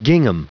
Prononciation du mot gingham en anglais (fichier audio)